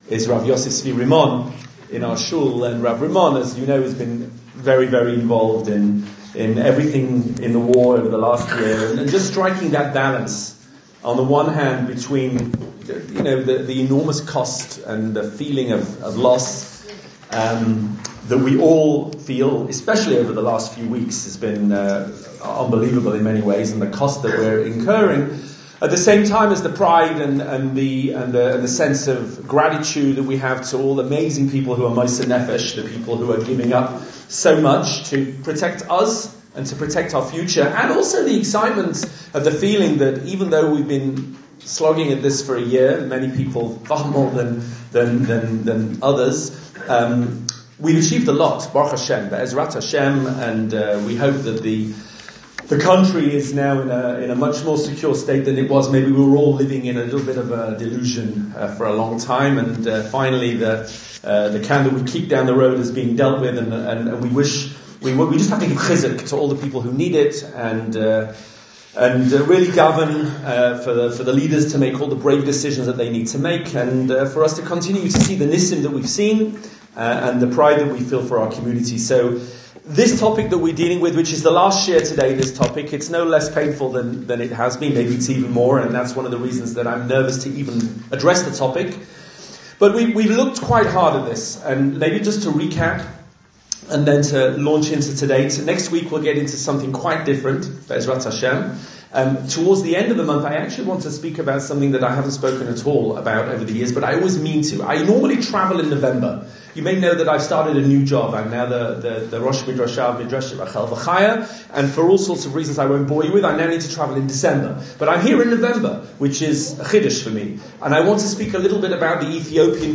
A series of 4 audio shiurim discussing Torah perspectives on the age-old challenge of suffering and why bad things happen to good people.